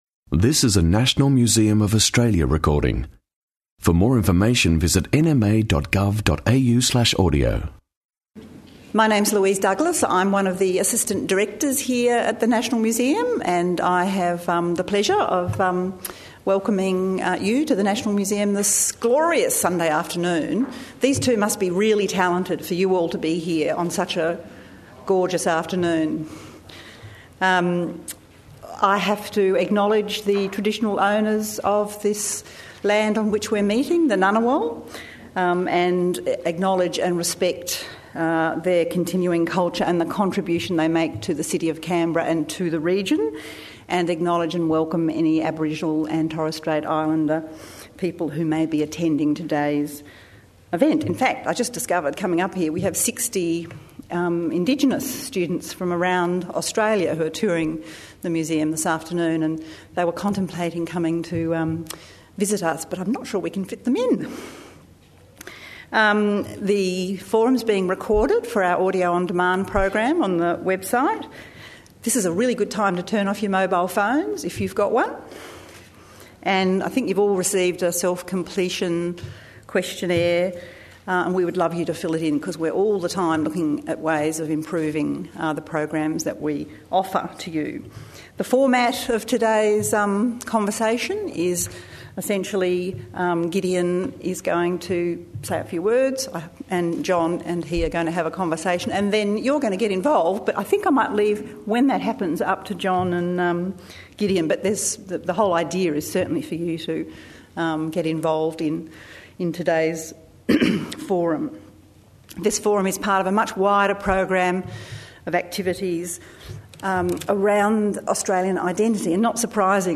20 Jun 2010 Public conversation